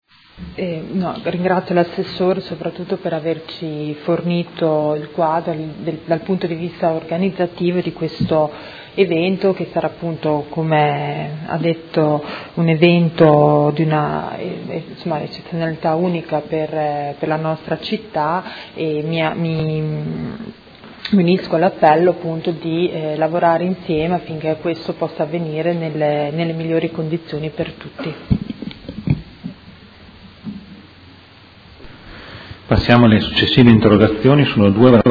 Seduta del 19/01/2017 Interrogazione dei Consiglieri Baracchi e Bortolamasi (P.D.) avente per oggetto: Sospensione concerto 1° luglio 2017 Vasco Rossi. Replica